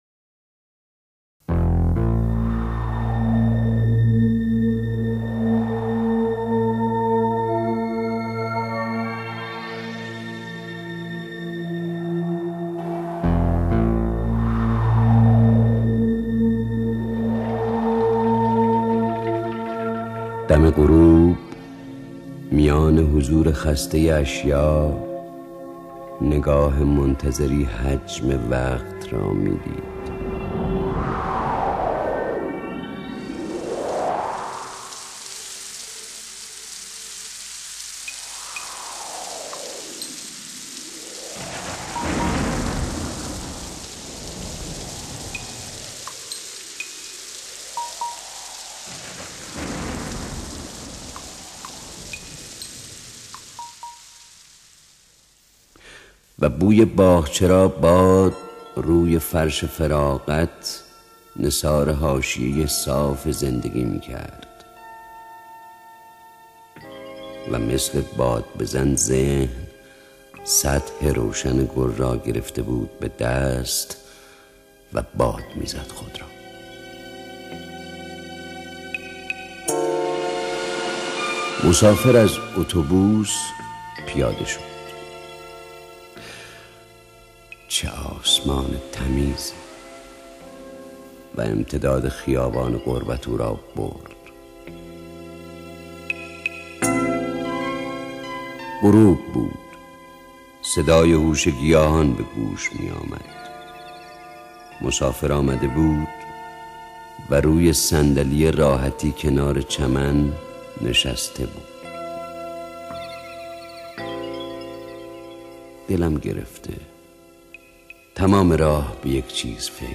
دانلود دکلمه مسافر  با صدای خسروشکیبایی
گوینده :   [خسـرو شکیبایی]